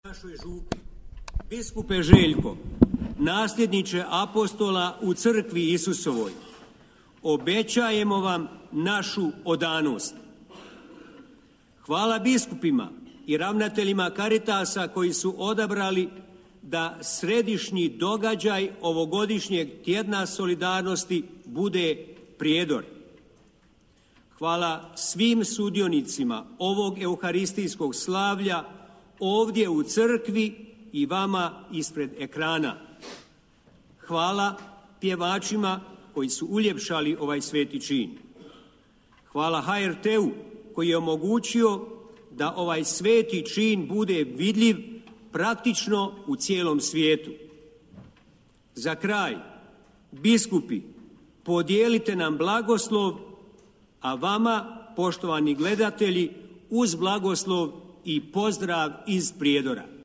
Euharistijsko slavlje u okviru Tjedna solidarnosti, koji se ove godine odvija pod motom „Solidarnost u deset riječi!“, izravno je prenosila Hrvatska Radiotelevizija na svom prvom kanalu, a izravni prijenos preuzimala je i RTV Herceg-Bosna.